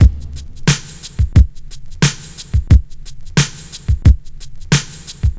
• 89 Bpm Modern Hip-Hop Drum Groove F# Key.wav
Free drum beat - kick tuned to the F# note. Loudest frequency: 1465Hz
89-bpm-modern-hip-hop-drum-groove-f-sharp-key-zYR.wav